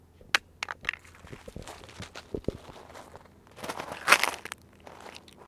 animalworld_crab.ogg